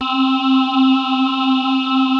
Index of /90_sSampleCDs/Wizoo - Powered Wave/PPG CHOIR